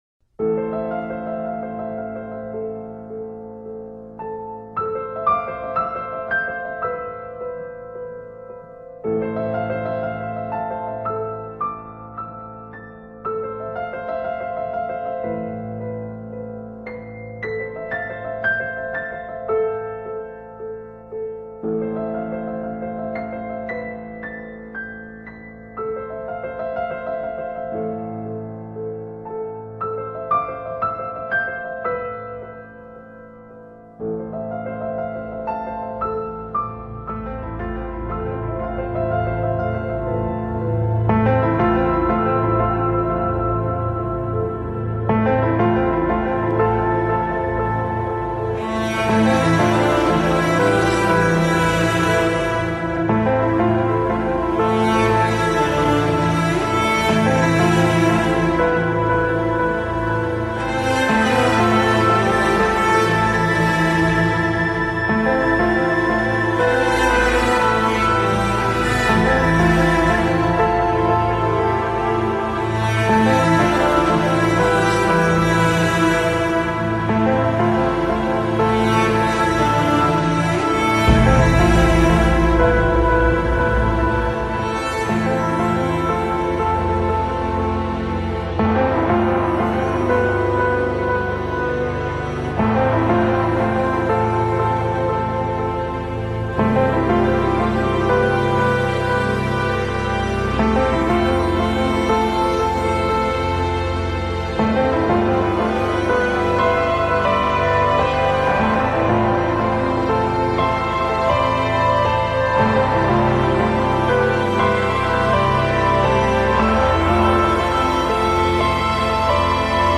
a piano and violin-driven version
piano